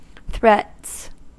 Ääntäminen
Ääntäminen US Haettu sana löytyi näillä lähdekielillä: englanti Threats on sanan threat monikko.